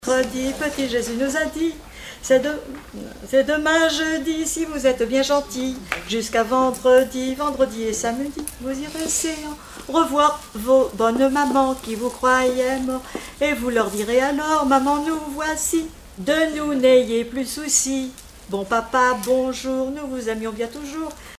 Genre énumérative
Chansons traditionnelles et populaires